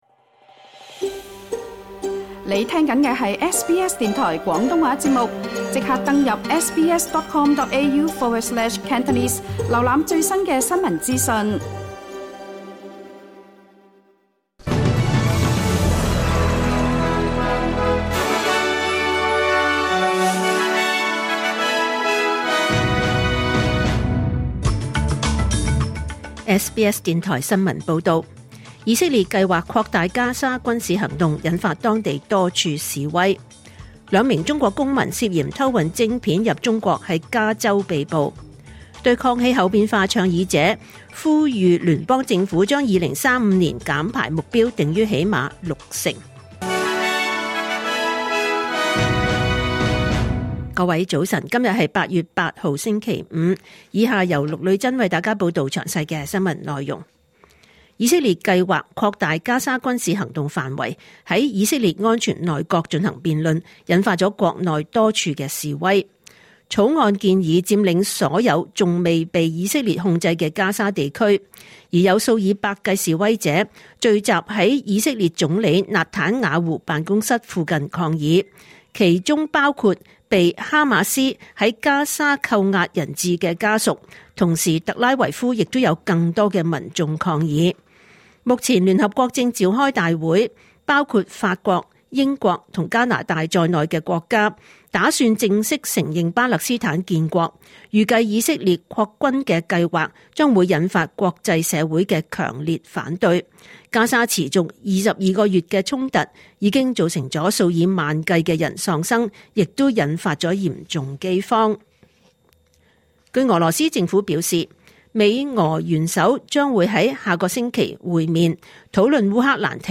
2025年8月8日SBS廣東話節目九點半新聞報道。